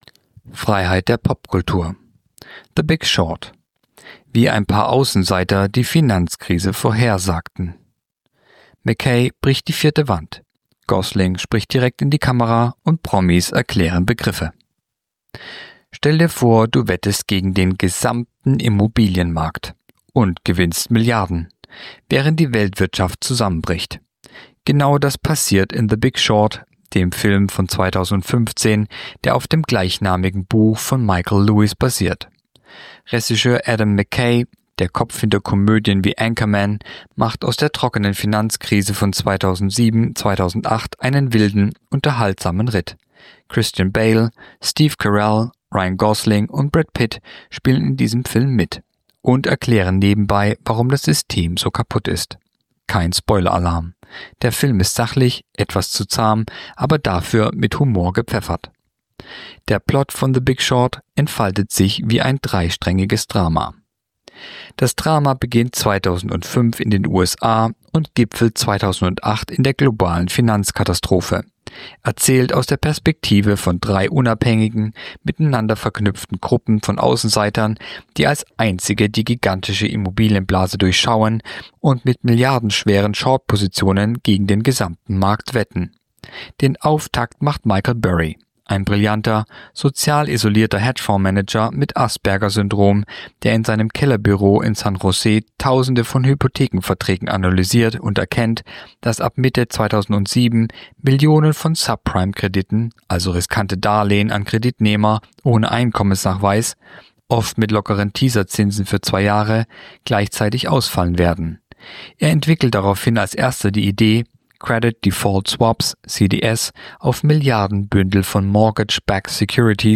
Kolumne der Woche (Radio)The Big Short: Wie ein paar Außenseiter die Finanzkrise vorhersagten